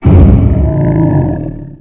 bangdoor2.wav